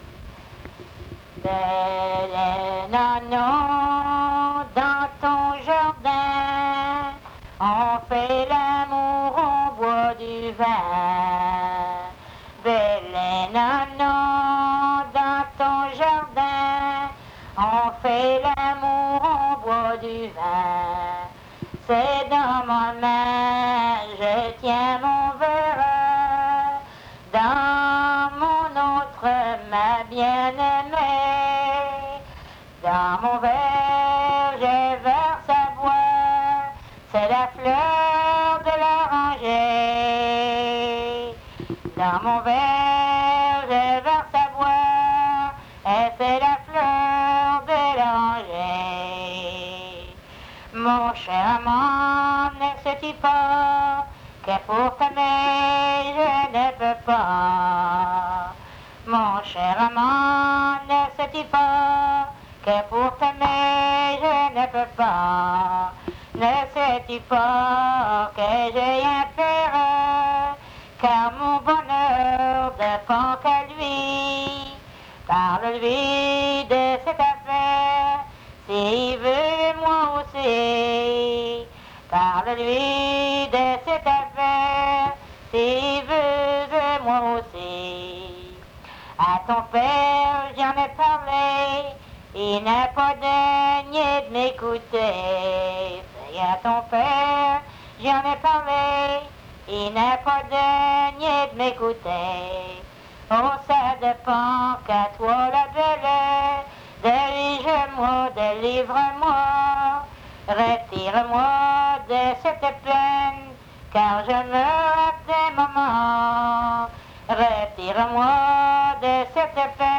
Chanson Item Type Metadata
Emplacement Cap St-Georges